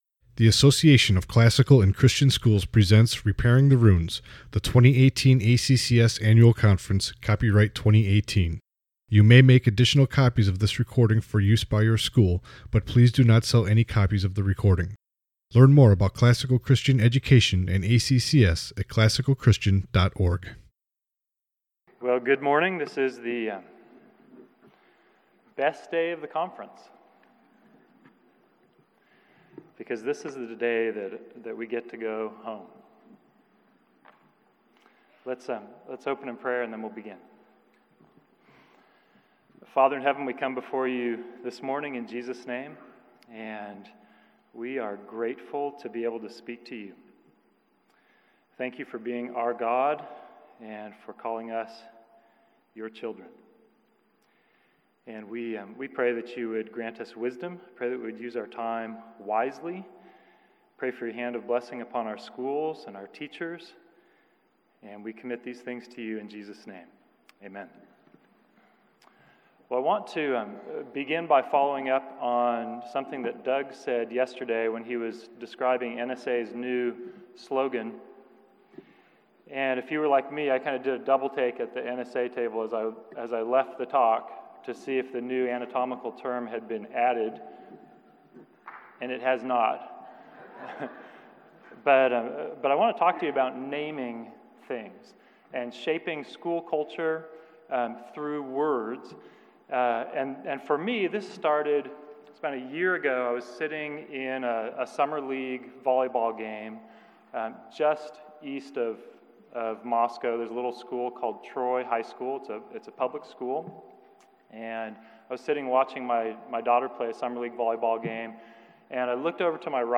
2018 Plenary Talk | 1:07:52 | All Grade Levels, Virtue, Character, Discipline